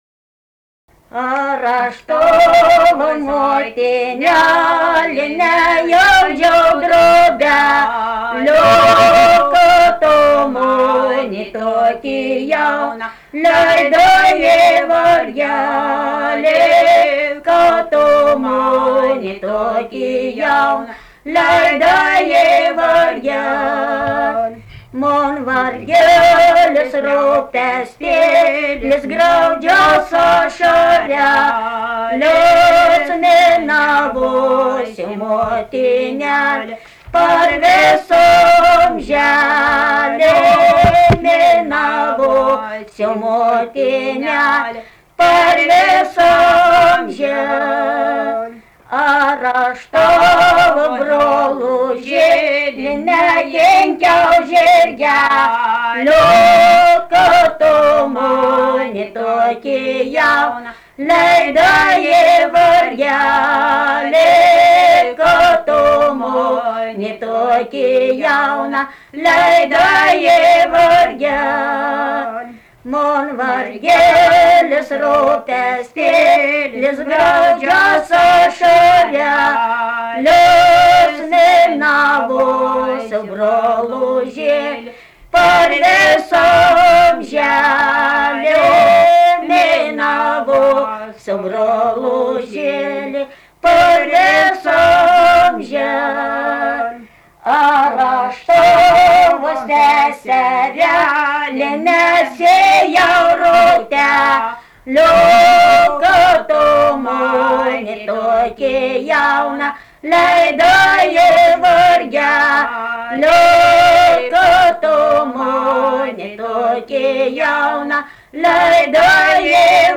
Dalykas, tema daina
Erdvinė aprėptis Biržuvėnai
Atlikimo pubūdis vokalinis